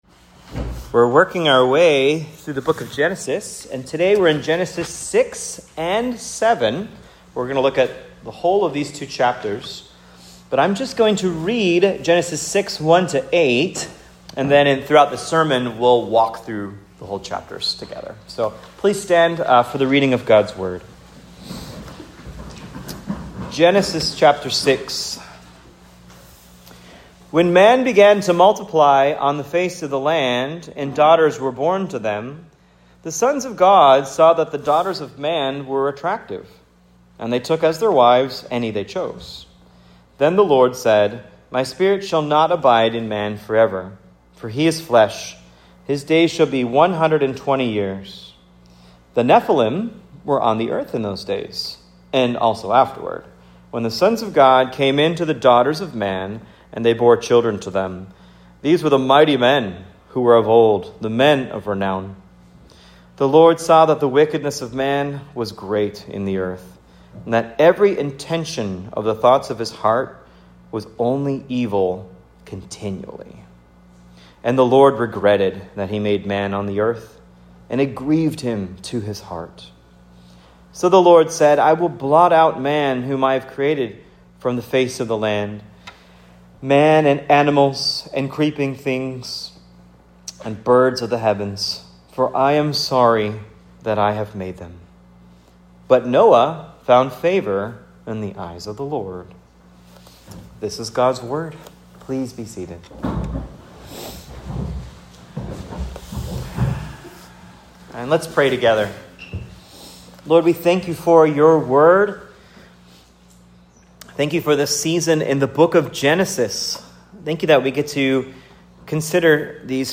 Preached at Cross of Christ Fellowship in Naperville, Illinois on 10/19/25.